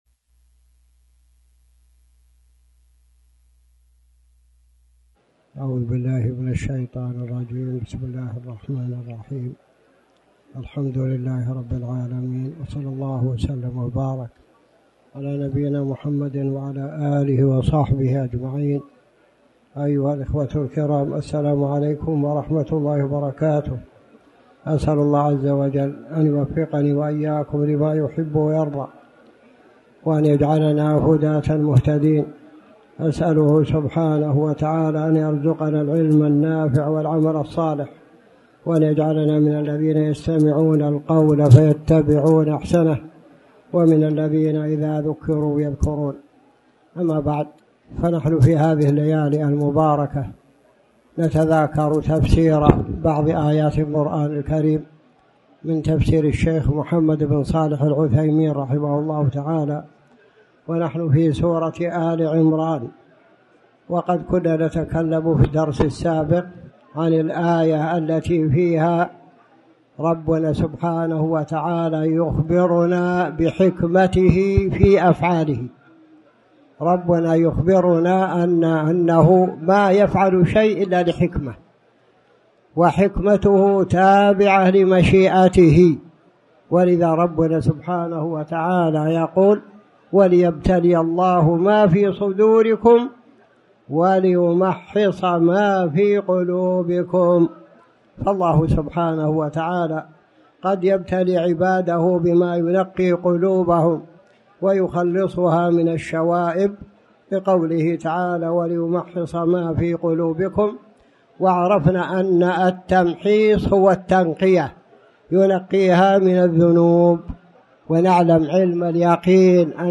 تاريخ النشر ٢١ رمضان ١٤٣٩ هـ المكان: المسجد الحرام الشيخ